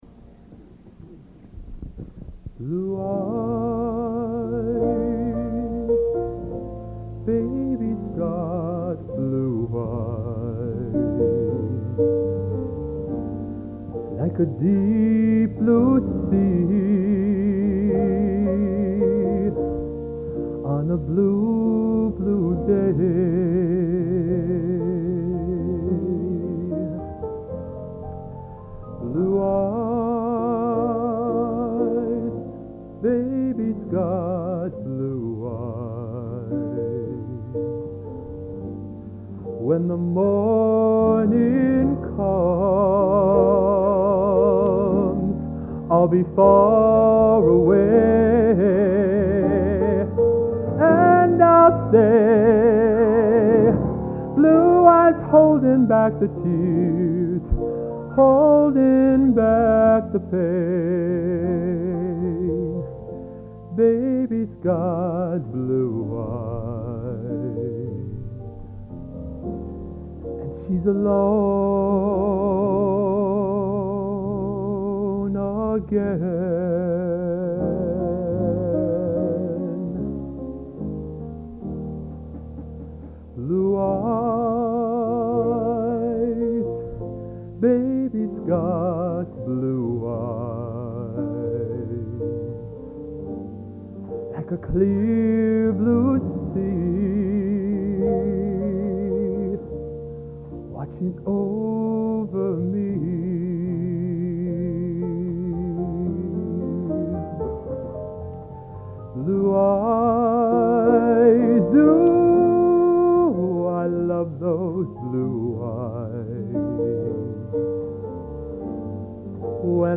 Location: West Lafayette, Indiana